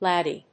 /lˈædi(米国英語)/